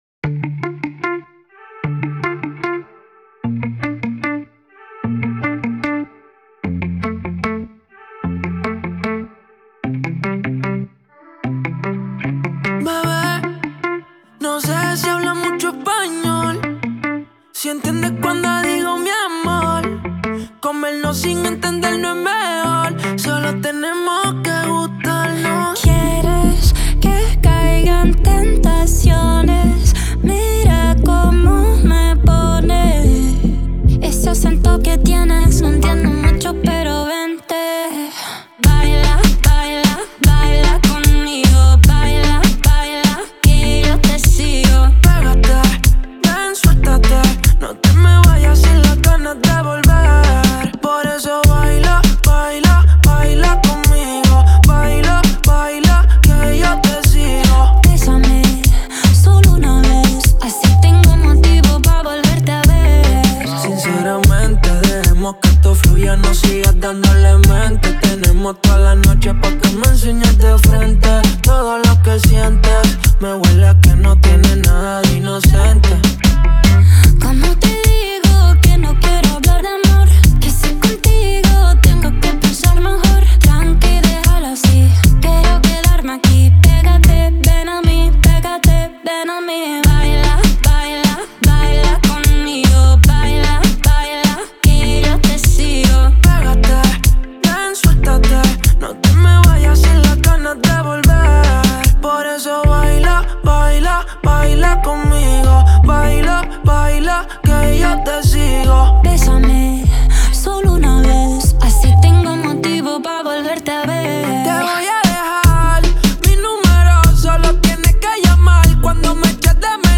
это зажигательная латинская поп-песня